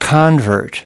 convert-noun.mp3